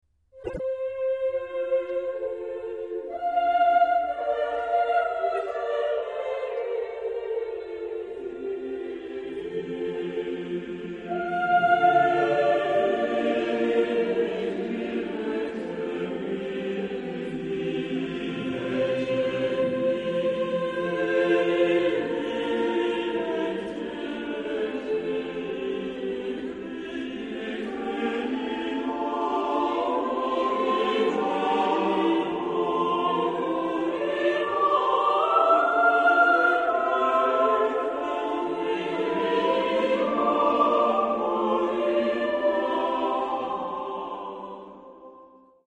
Genre-Style-Form: Renaissance ; Sacred ; Motet
Type of Choir: SATTB  (5 mixed voices )
Tonality: C major